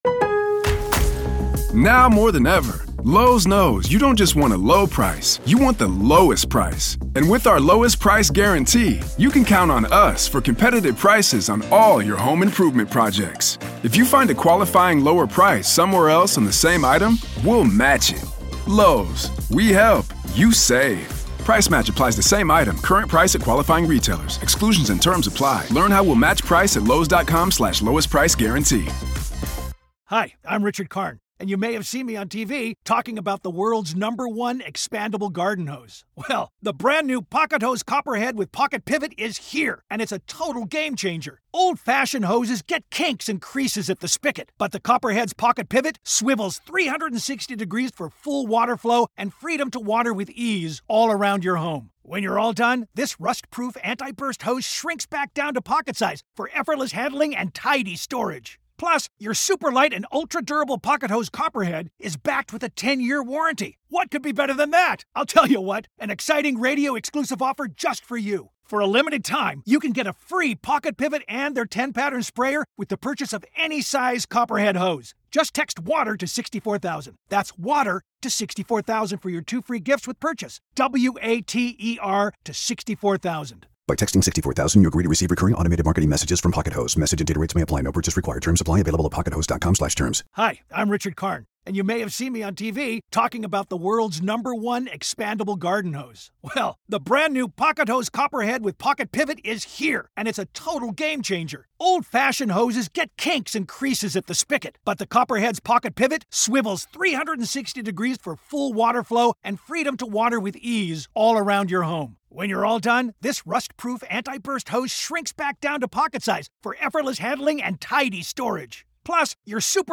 This is audio from the courtroom in the high-profile murder conspiracy trial of Lori Vallow Daybell in Arizona.